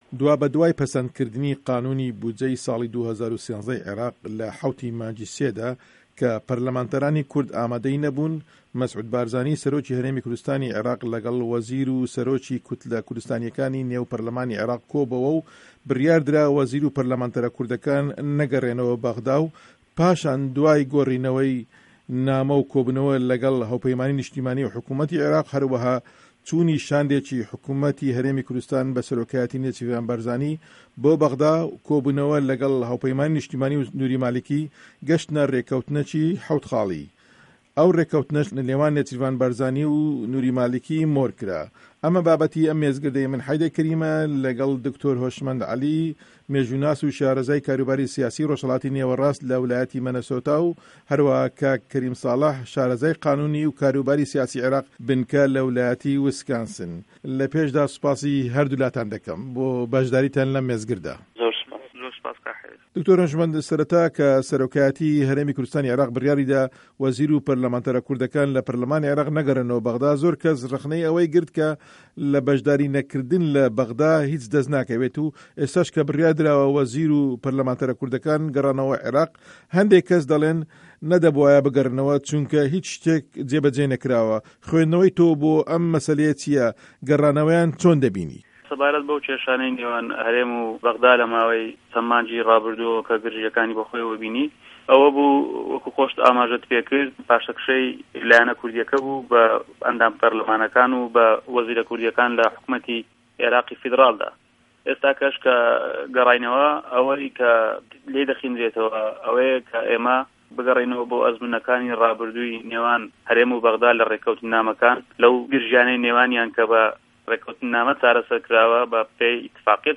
مێزگرد: ڕێکه‌وتنه‌ 7 خاڵیـیه‌که‌ی نێوان هه‌رێم و حکومه‌تی مالیکی